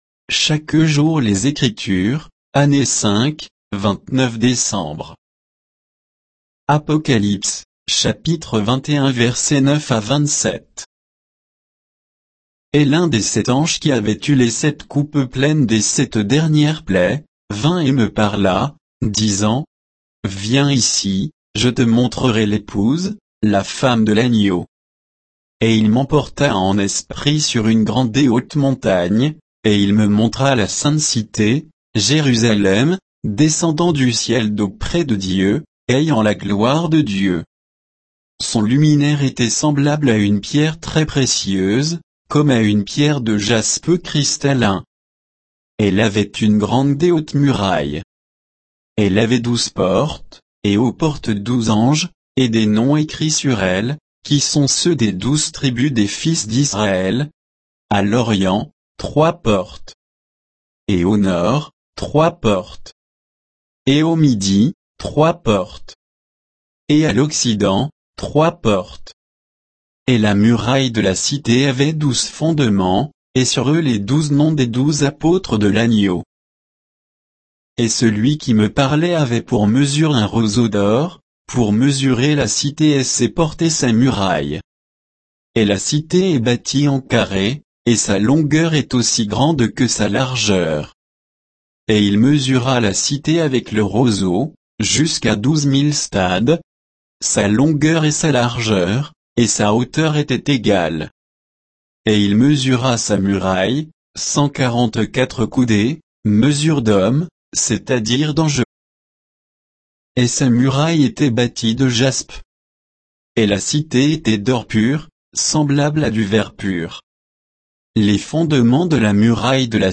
Méditation quoditienne de Chaque jour les Écritures sur Apocalypse 21